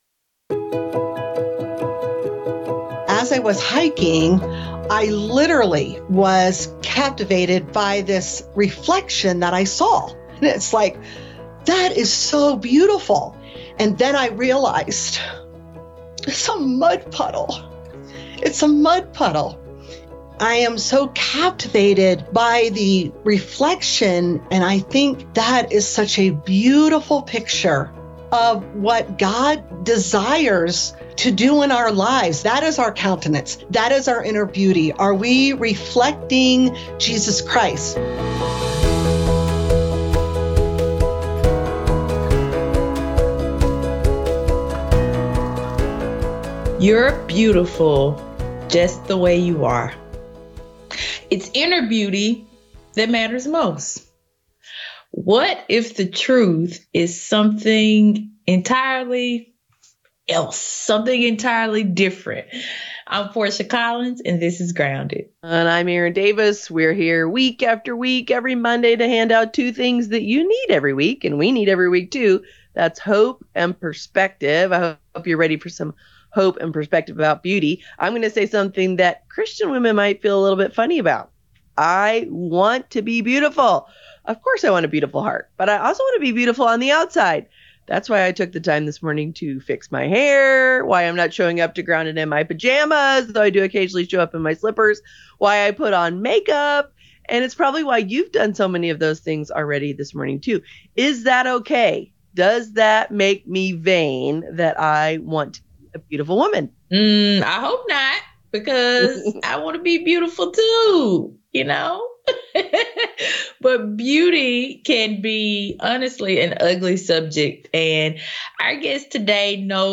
Her interview will point you to God’s Word and help you discover a biblical perspective on both inner and outer beauty.